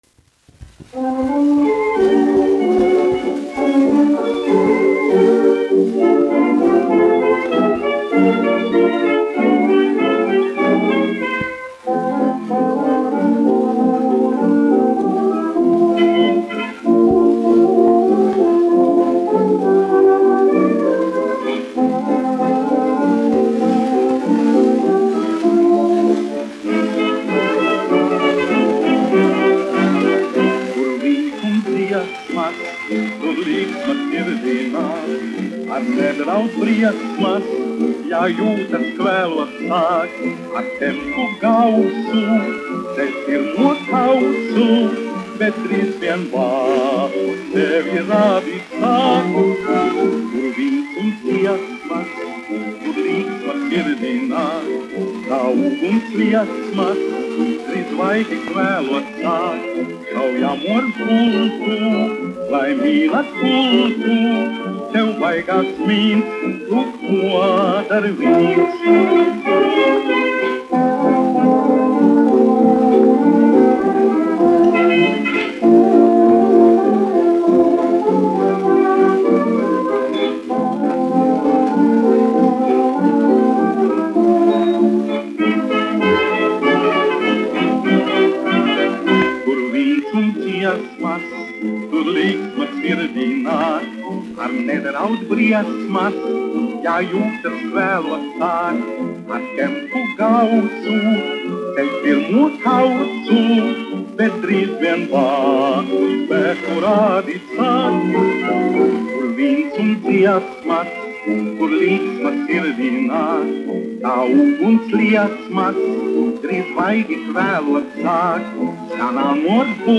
1 skpl. : analogs, 78 apgr/min, mono ; 25 cm
Fokstroti
Populārā mūzika
Skaņuplate